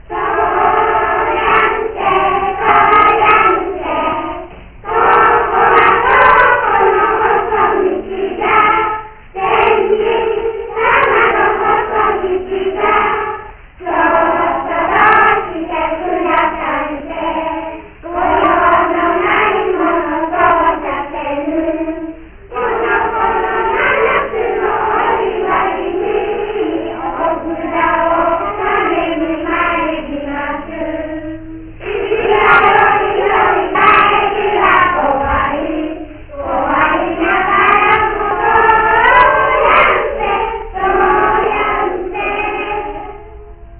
この天神様は、わらべ唄「とおりゃんせ」発祥の地といわれています。